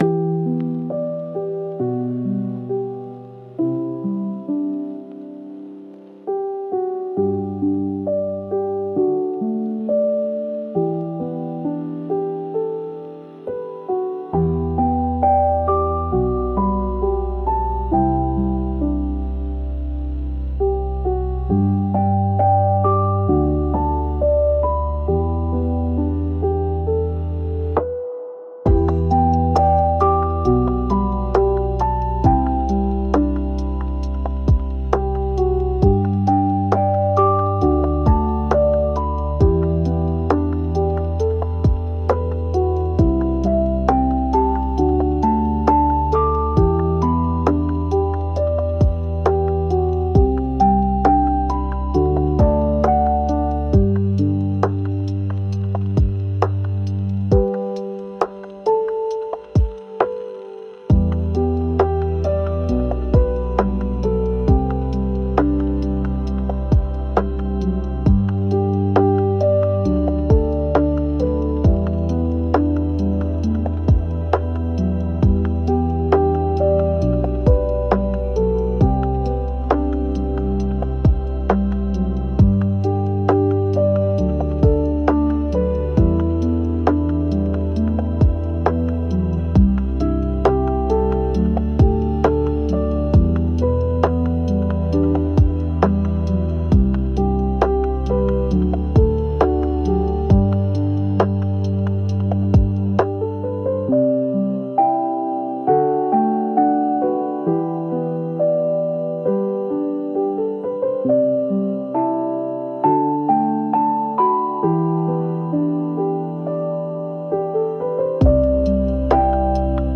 「悲しい」